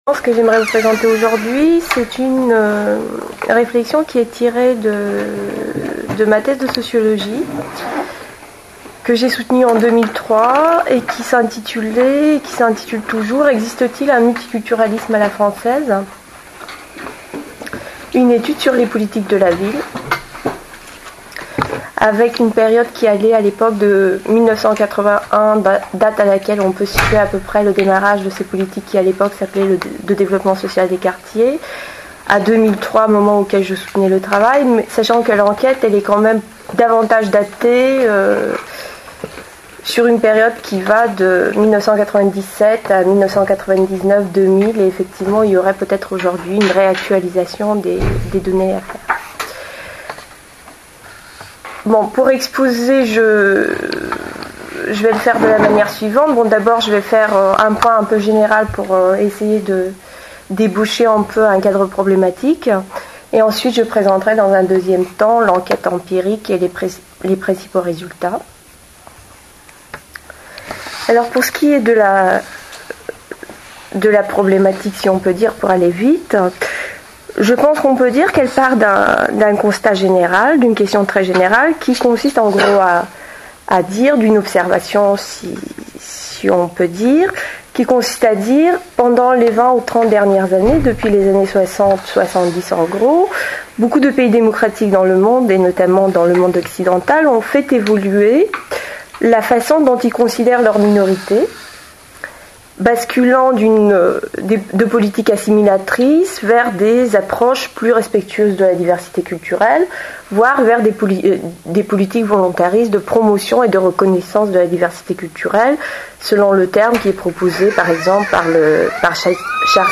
S�minaire RESO - relations interethniques, racisme et discriminations